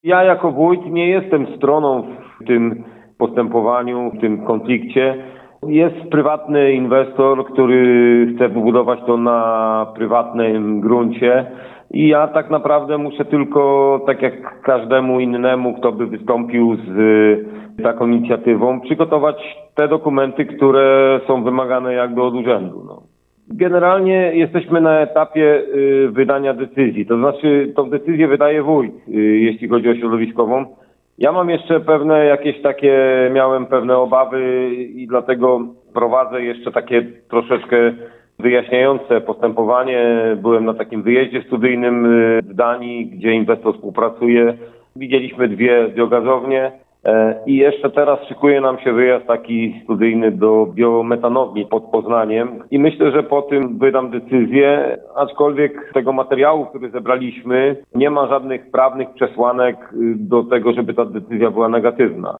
Wójt Nowogródka Pomorskiego, Krzysztof Mrzygłód, twierdzi, że gmina nadal jest na etapie zbierania materiałów dotyczących tej inwestycji, a decyzja środowiskowa zostanie wydana dopiero po zakończeniu tego procesu: